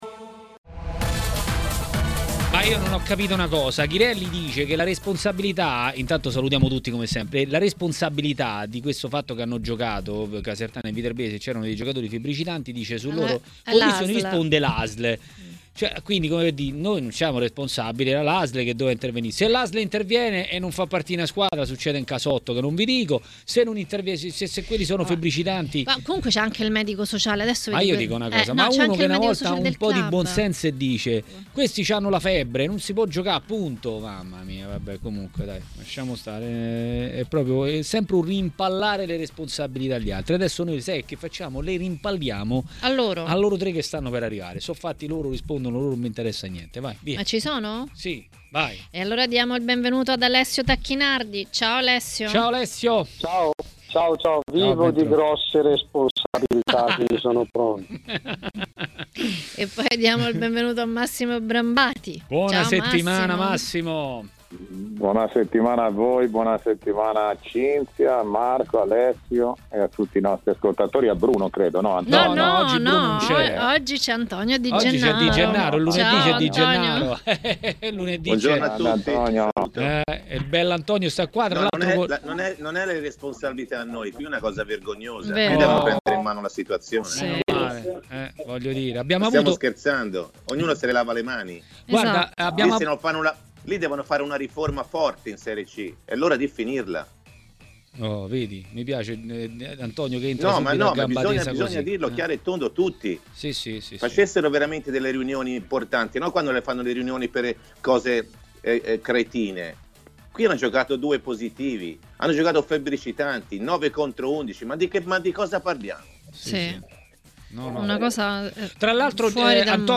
A parlare dei temi del giorno a Maracanà, nel pomeriggio di TMW Radio, è stato l'ex calciatore e opinionista tv Antonio Di Gennaro.